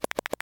settings-menu-intro.mp3